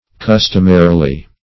Customarily \Cus"tom*a*ri*ly\ (-[asl]*r[i^]*l[y^]), adv.